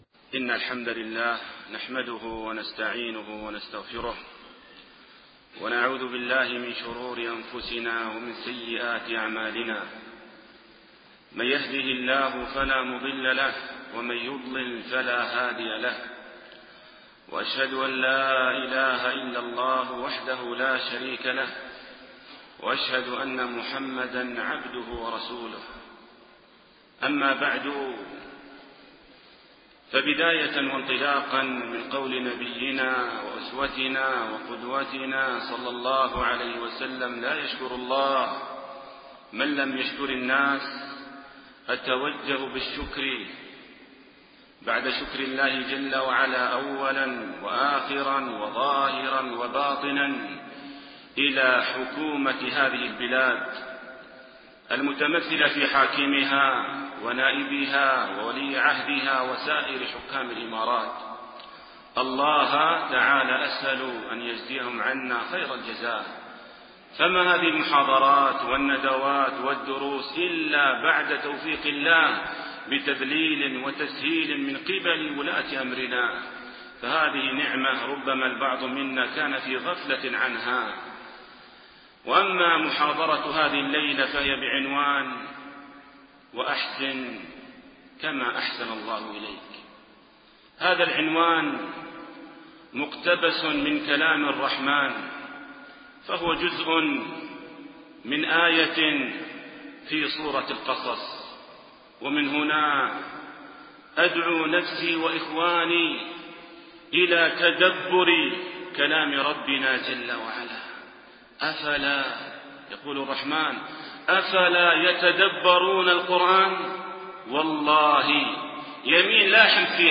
محاضرة بعنوان: وأحسن كما أحسن الله إليك لـ